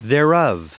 Prononciation du mot thereof en anglais (fichier audio)
Prononciation du mot : thereof